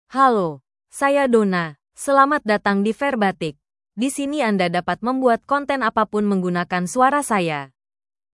DonnaFemale Indonesian AI voice
Donna is a female AI voice for Indonesian (Indonesia).
Voice sample
Listen to Donna's female Indonesian voice.
Female